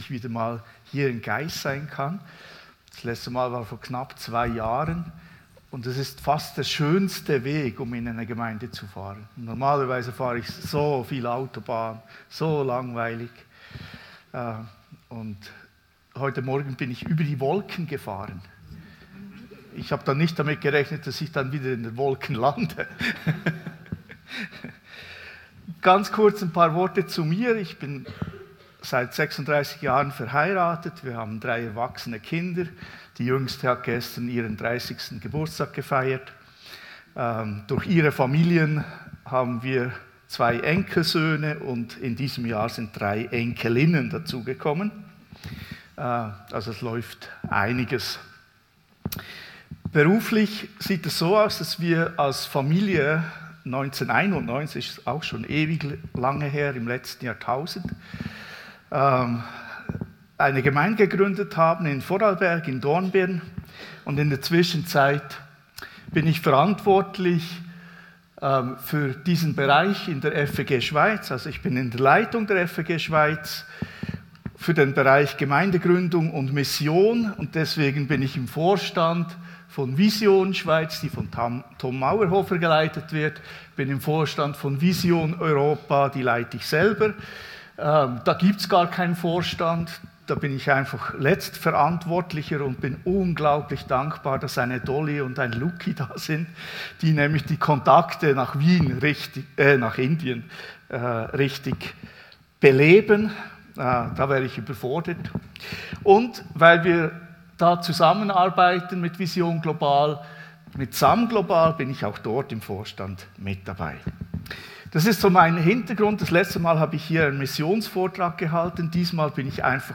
Predigt 9. Juni 2024